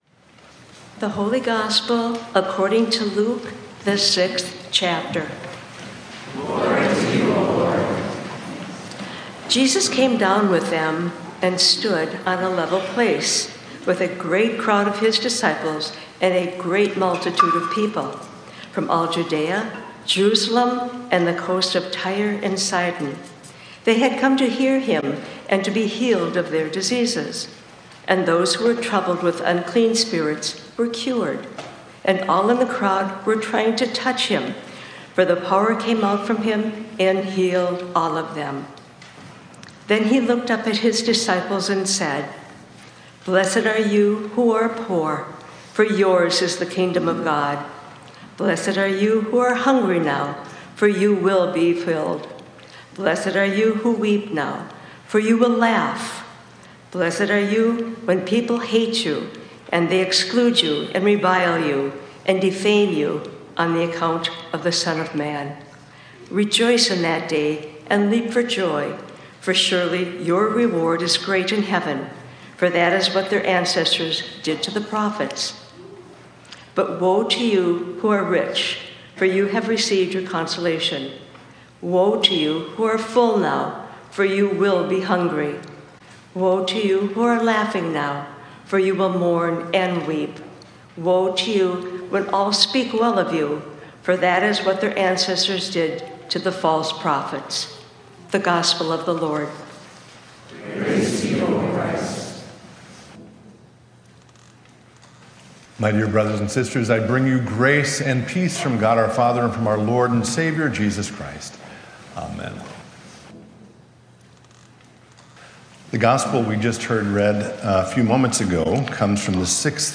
Includes sermons from our Sunday morning 9:45 worship services.